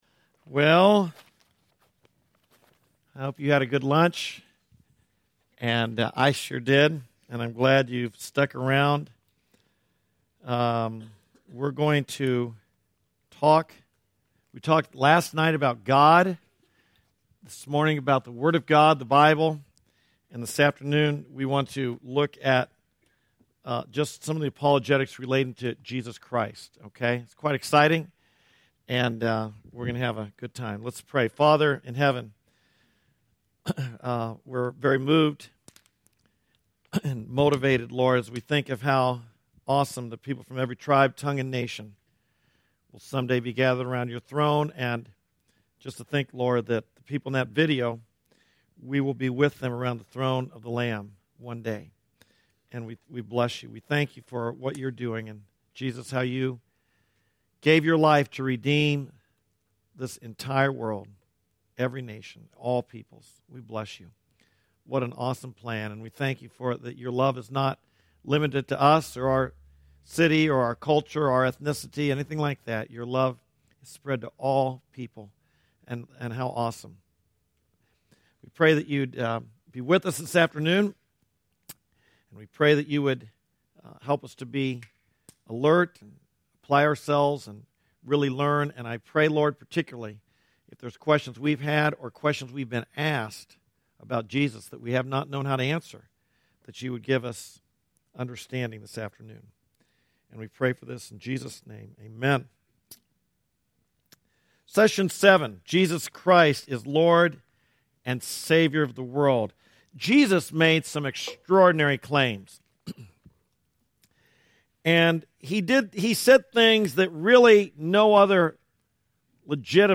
Our audio sermon podcast is available on most podcasting services including Spotify, Apple Podcasts, Stitcher, Google Podcasts and more!